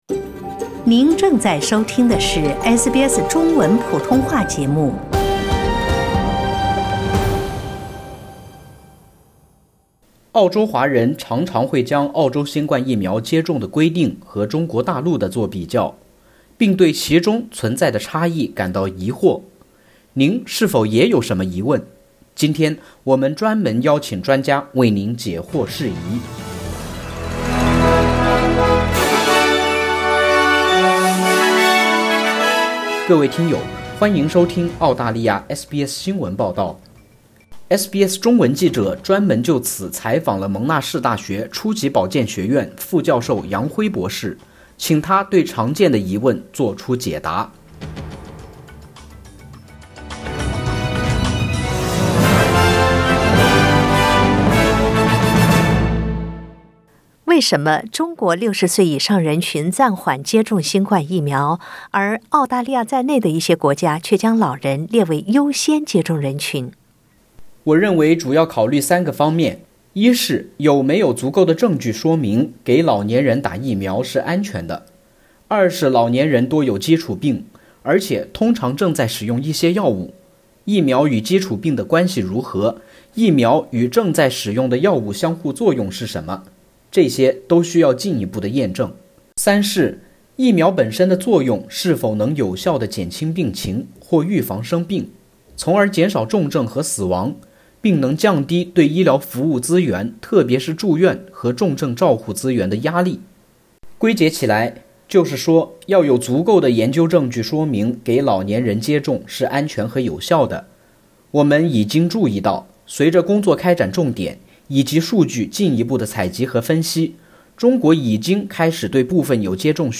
澳洲專家為您釋疑解惑。